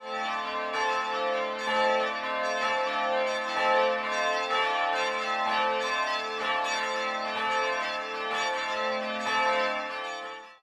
Kliknij przycisk PLAY w odtwarzaczu poniżej i posłuchaj, jak brzmiały dzwony naszego kościoła przed II wojną światową.
dzwony1945.wav